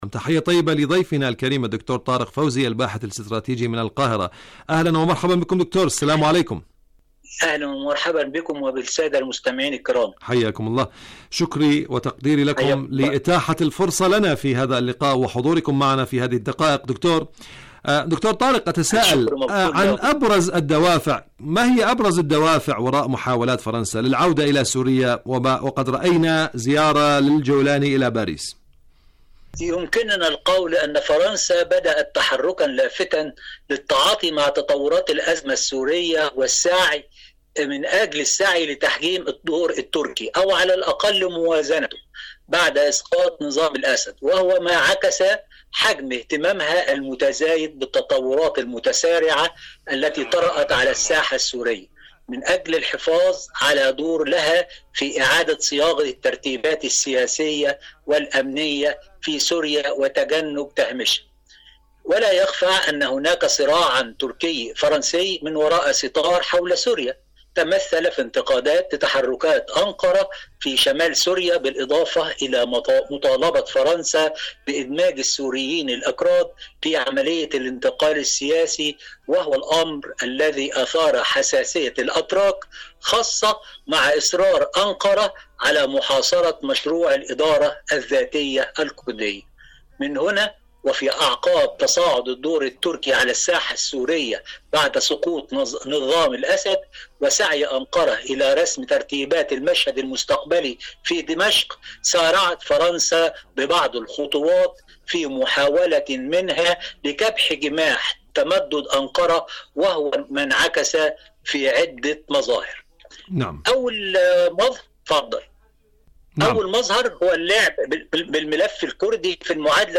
مقابلات برامج إذاعة طهران العربية برنامج حدث وحوار مقابلات إذاعية الطباع الخبيثة لن تسقط بالتقادم شاركوا هذا الخبر مع أصدقائكم ذات صلة القرار المسيس للوكالة الدولية للطاقة النووية..